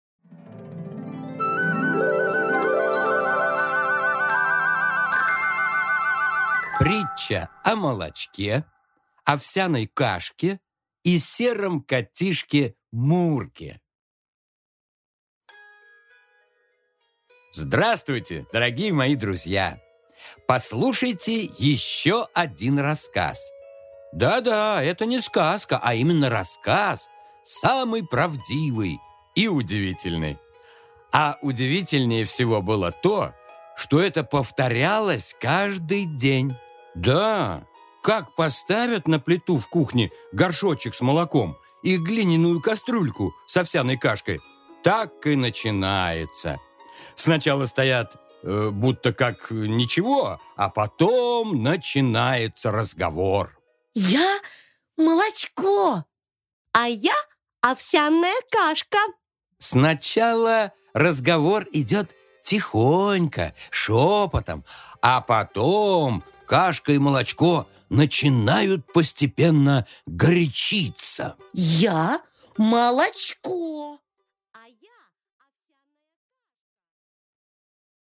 Аудиокнига Притча о молочке, овсяной кашке и сером котишке мурке | Библиотека аудиокниг